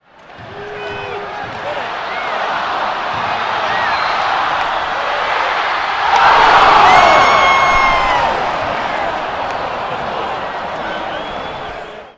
Звуки болельщиков
Звук криков болельщиков из-за не забитого гола рядом с воротами